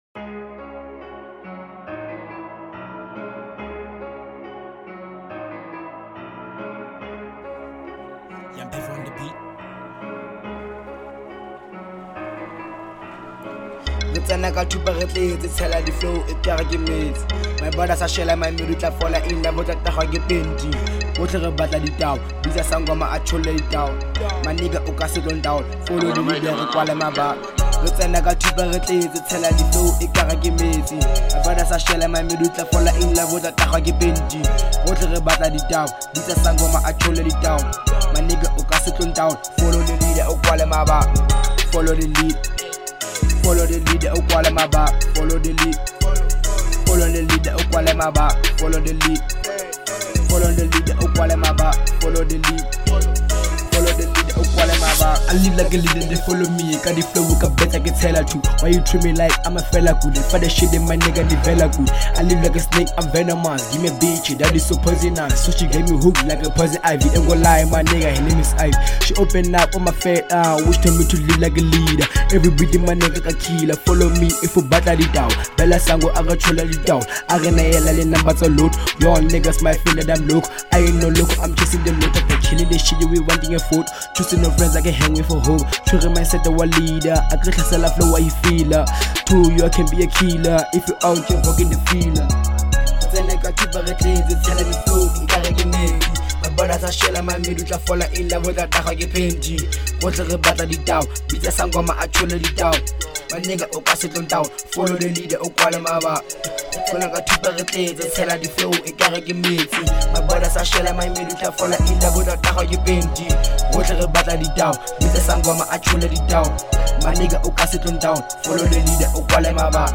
03:50 Genre : Trap Size